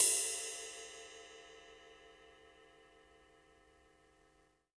D2 RIDE-01-L.wav